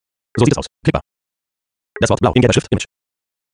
Aber zu den Tondateien - die sind irgrndwie so wie 5x schneller abgespielt - kannst Du mal danach schauen?
Also der muss so schnell sein, damit man schnell durch den Wald aus wiederholendem Text durchpflügen kann.